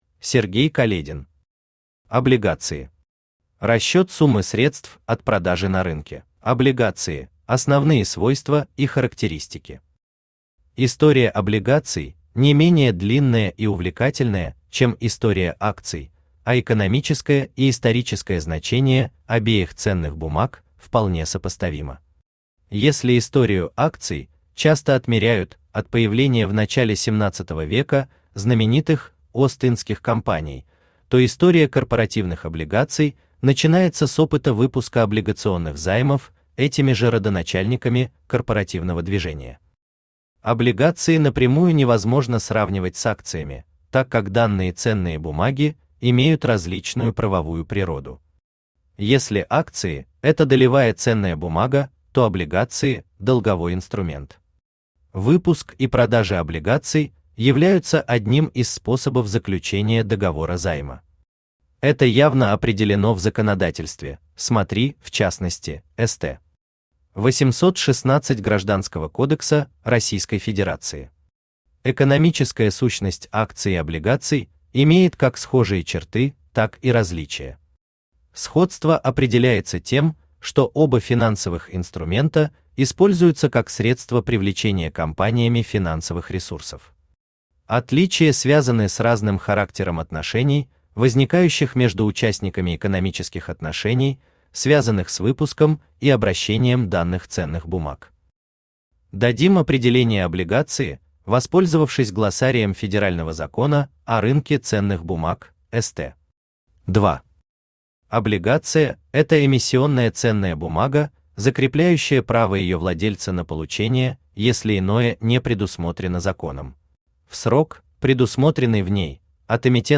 Аудиокнига Облигации. Расчёт суммы средств от продажи на рынке | Библиотека аудиокниг
Расчёт суммы средств от продажи на рынке Автор Сергей Каледин Читает аудиокнигу Авточтец ЛитРес.